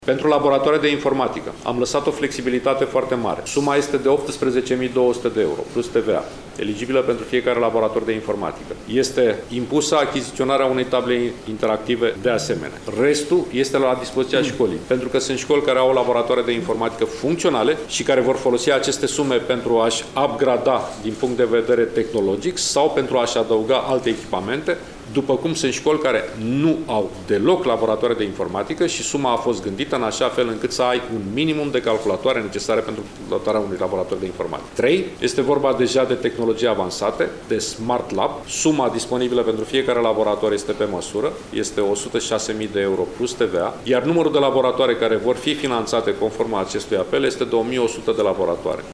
Astăzi, la Iași, Ministrul Educației, Sorin Cîmpeanu, a precizat că, pentru dotarea unui laborator de informatică, vor fi rezervați 18 mii 200 de euro.